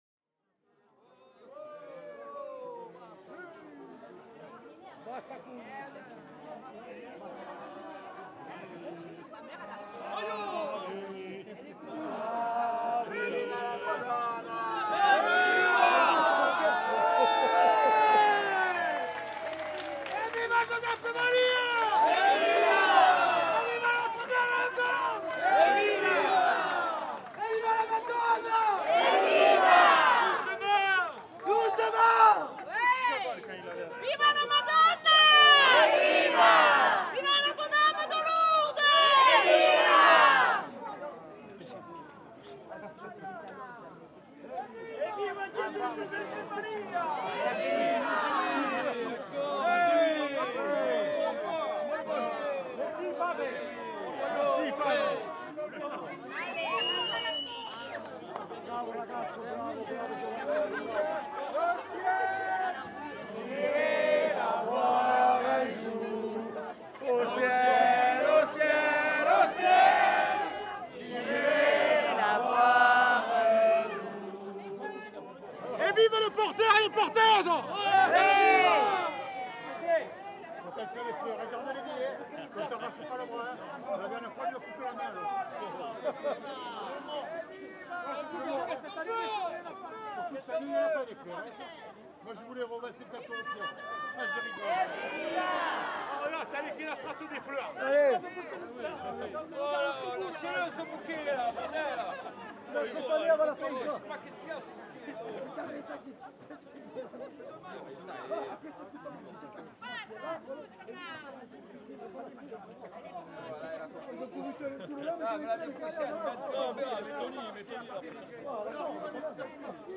Madonna Cathédrale de la Major | Marseille, France Portée par les cris, les rires et les chants, la procession du 15 août rejoint la cathédrale.Shouts, laughters and songs drive the Assumption day…